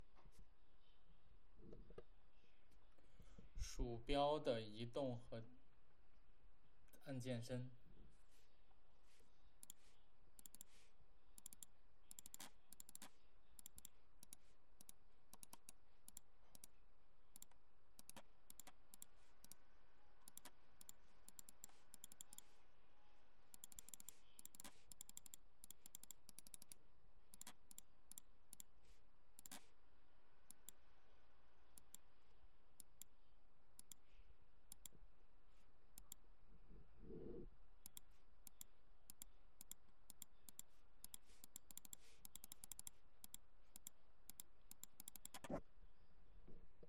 描述：简单的主音，清晰的声音，最后有一些颤音。采样自ATCX，E5调。
标签： 清晰